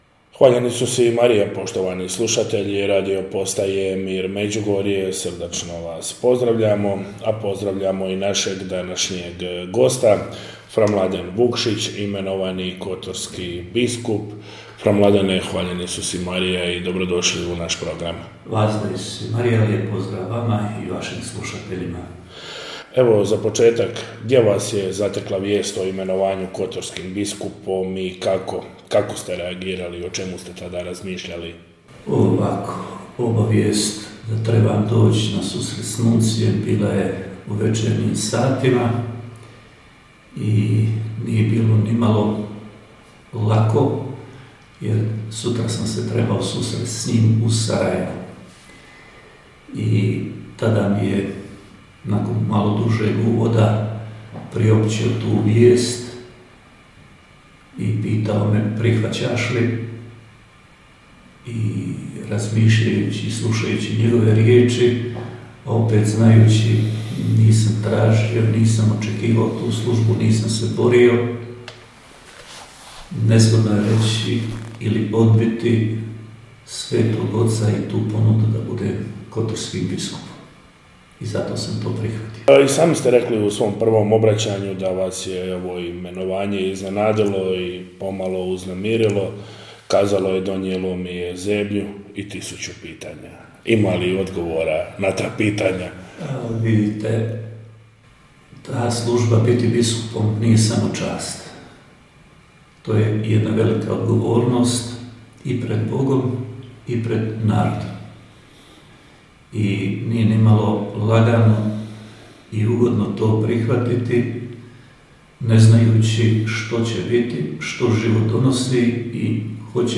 INTERVJU - Fra Mladen Vukšić, imenovani biskup kotorski: I dalje ću nositi svoj franjevački habit - Radio Mir